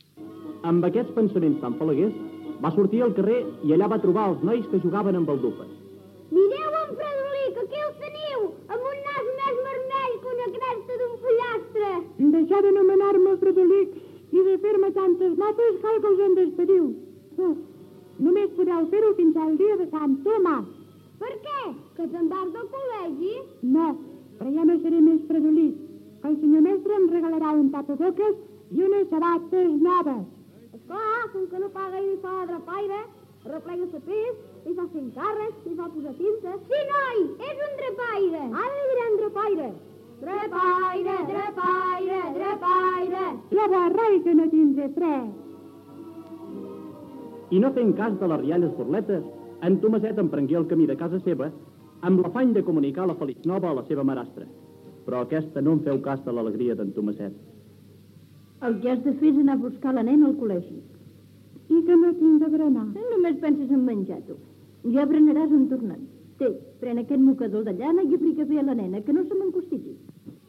Fragments de la versió radiofònic del conte "El fredolic"
Infantil-juvenil